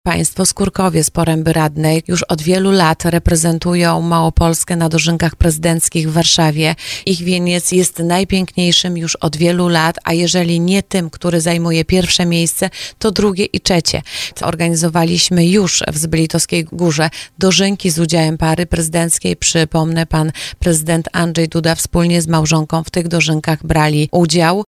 Jak tłumaczy poseł PiS Anna Pieczarka i mieszkanka gminy Tarnów, wybór regionu nie był przypadkowy.